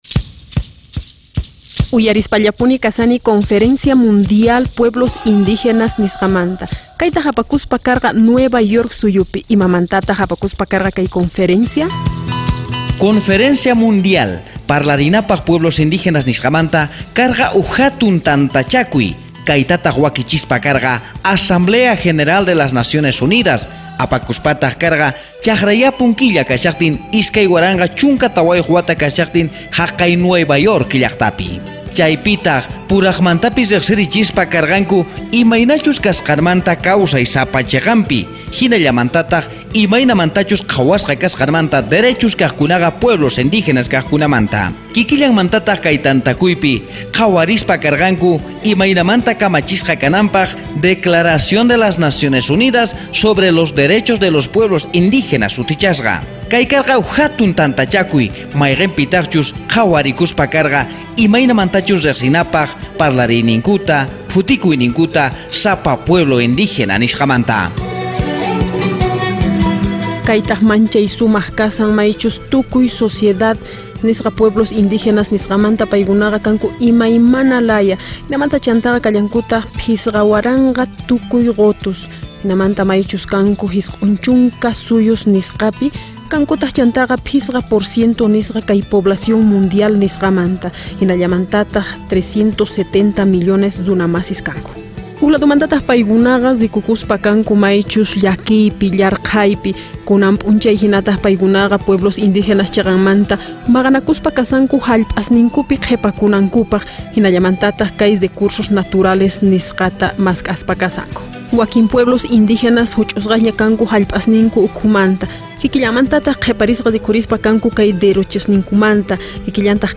Estas entrevistas tratan de la reunión de la Asamblea General de la ONU conocida como la Conferencia Mundial de los Pueblos Indígenas, que se realizó el 22 y 23 de septiembre del 2014. Se escuchará análisis y crítica del estatus de observador que tuvo la mayoría de la gente indígena que asistió; la intervenciones de los representantes de México y Costa Rica; y estrategias para hacer cumplir a los estados con el Documento Final.